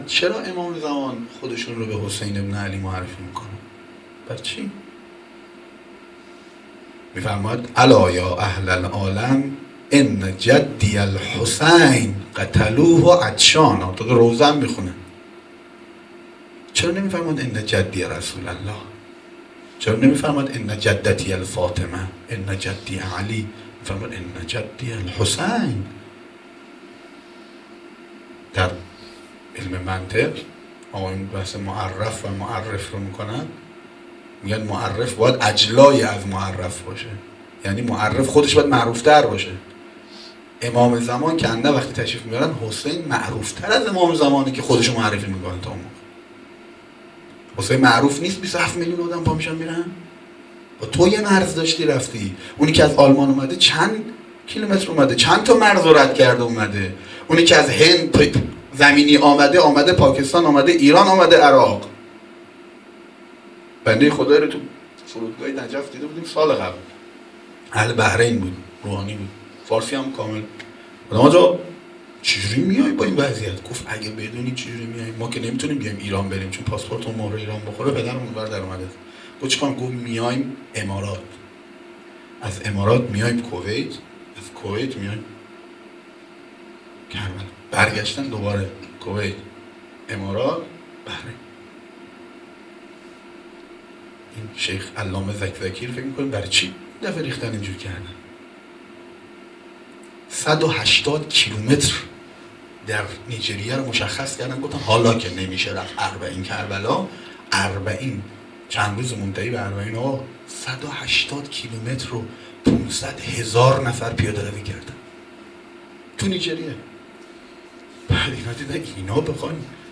سخنرانی - بخش2.m4a